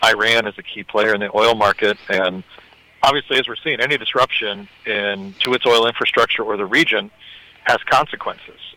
Some of us are grumbling about greedy oil companies using Operation Epic Fury as an excuse to raise prices at the pump, but one industry expert says there’s a lot more to the situation.